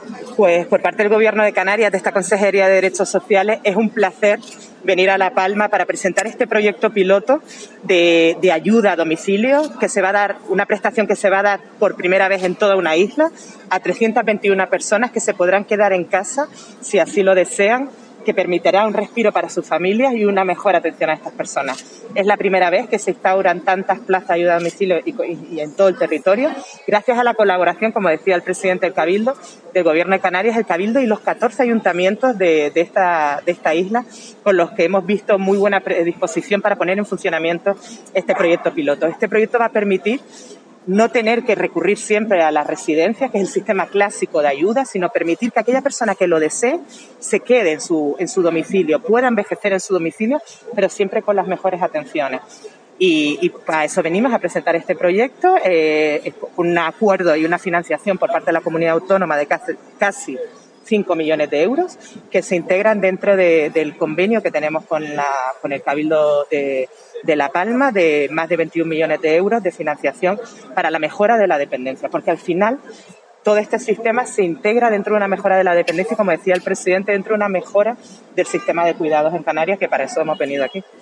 Consejera Derechos Sociales GobCan, Gemma Martínez.mp3